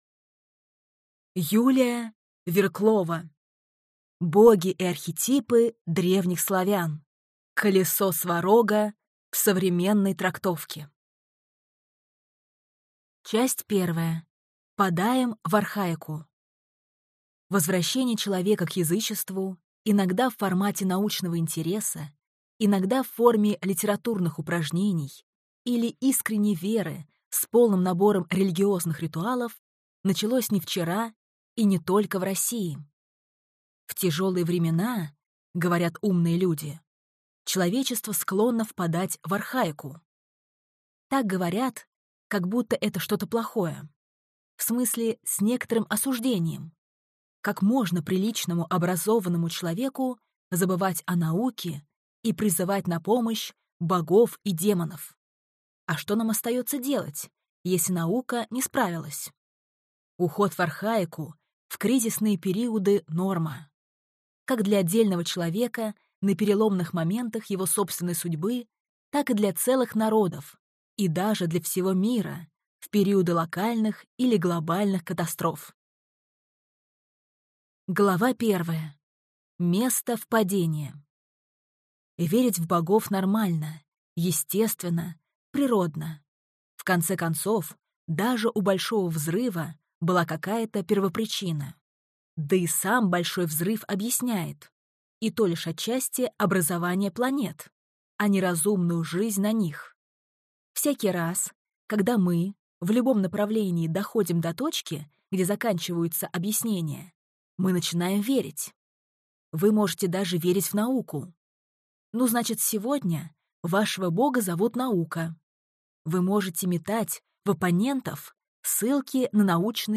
Аудиокнига Боги и архетипы древних славян. Колесо Сварога в современной трактовке | Библиотека аудиокниг